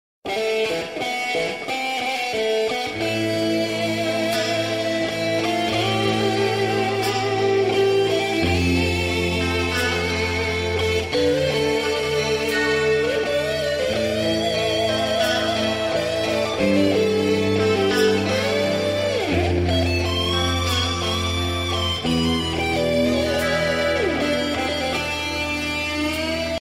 Kategorie SMS